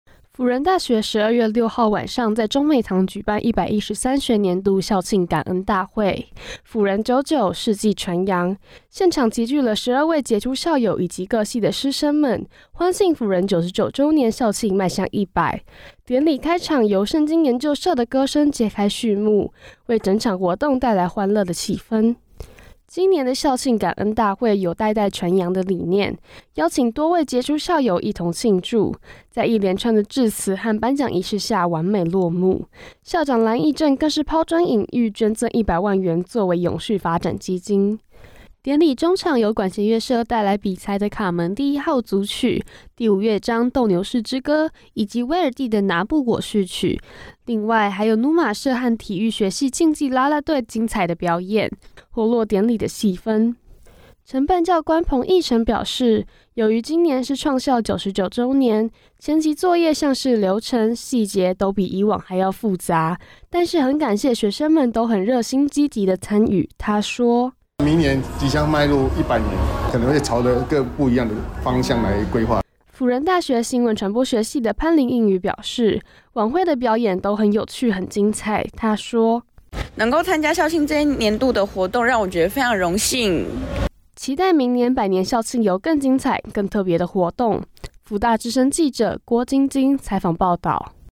輔仁大學十二月六號晚上在中美堂舉辦輔仁久久，世紀傳揚 校慶感恩大會，現場齊聚各系的師生們以及許多傑出校友，典禮開場由聖經研究社的歌聲揭開序幕，為整場活動帶來歡樂的氣氛。